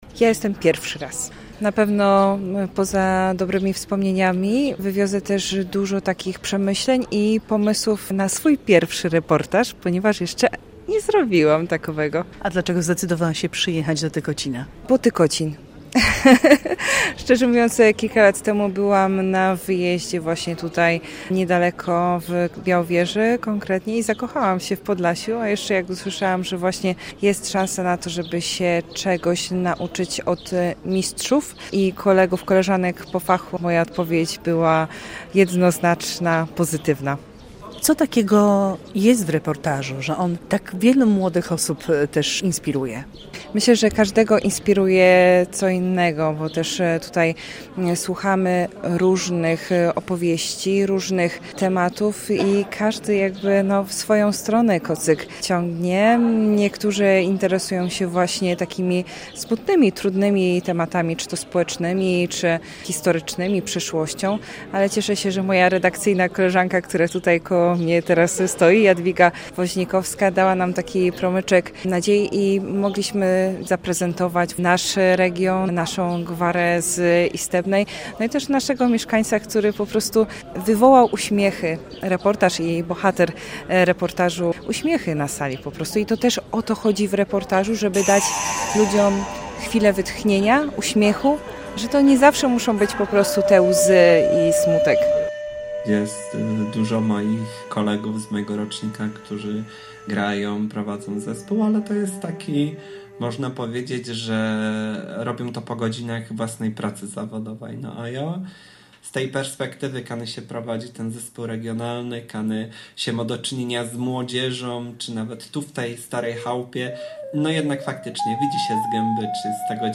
Warsztaty reportażu radiowego - relacja